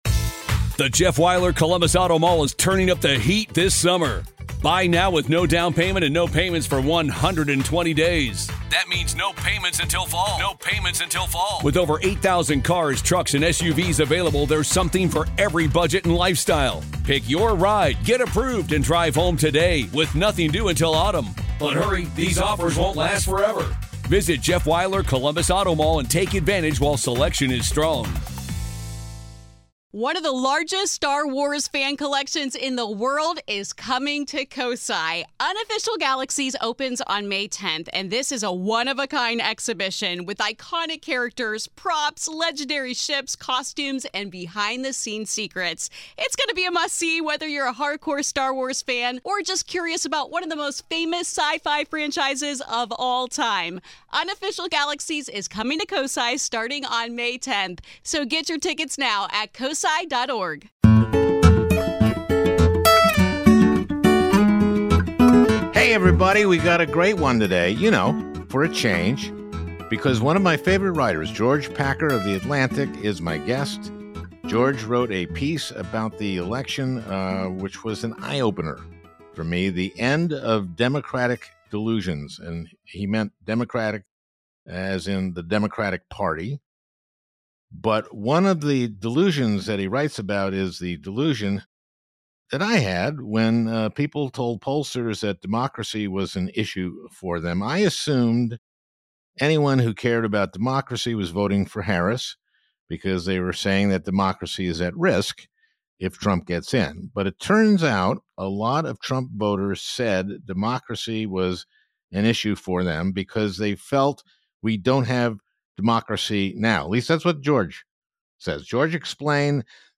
The Atlantic's George Packer joins us to discuss his recent piece titled The End of Democratic Delusions. Packer discusses the new era that Donald Trump has ushered in and what that means to politics moving forward. Trump won a close election, but it's a moment for the Democratic establishment to take a look at their shortcomings and redevelop their messaging and platform in the years ahead.